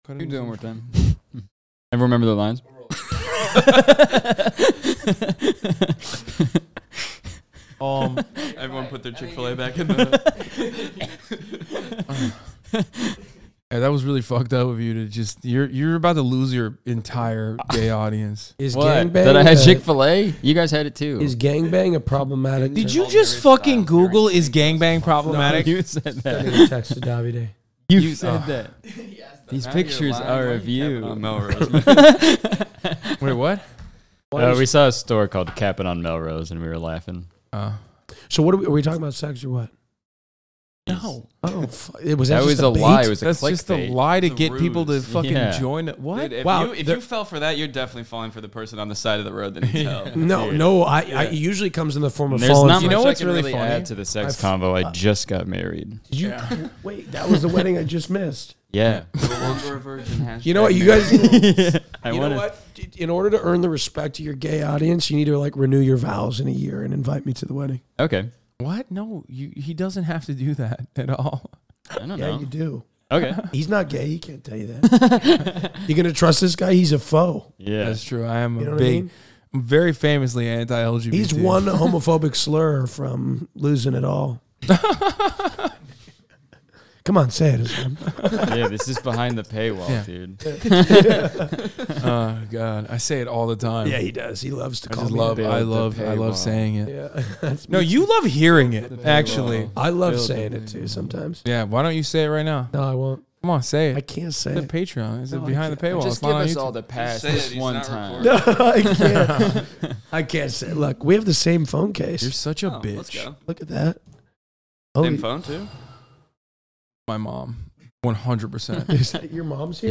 jesus that opera note at the end got me surprised